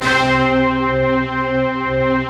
Index of /90_sSampleCDs/Optical Media International - Sonic Images Library/SI1_StaccatoOrch/SI1_Sfz Orchest